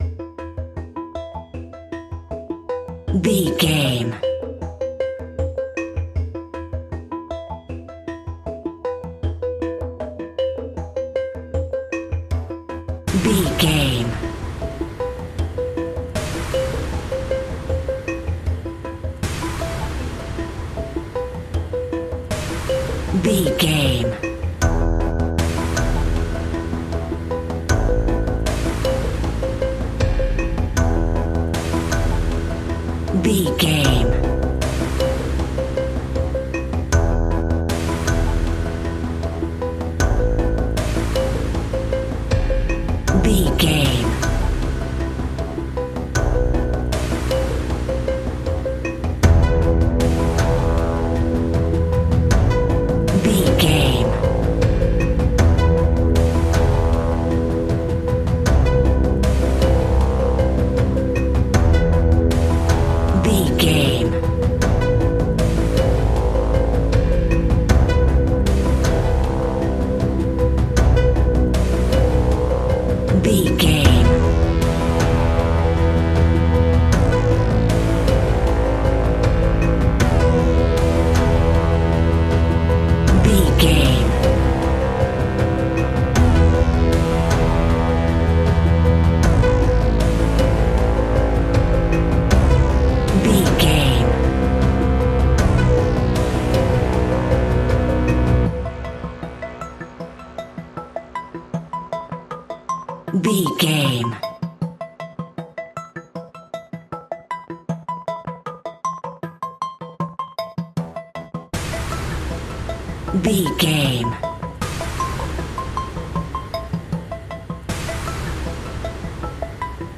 Synth Metal Full.
In-crescendo
Aeolian/Minor
ominous
dark
eerie
synthesizer
electronic music
Horror Synths